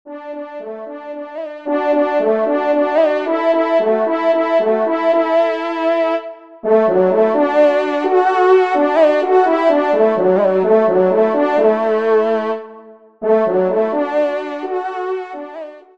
Genre : Musique Religieuse pour Quatre Trompes ou Cors
Pupitre 2°Trompe